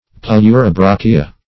Search Result for " pleurobrachia" : Wordnet 3.0 NOUN (1) 1. sea gooseberries ; [syn: Pleurobrachia , genus Pleurobrachia ] The Collaborative International Dictionary of English v.0.48: Pleurobrachia \Pleu`ro*brach"i*a\, n. [NL.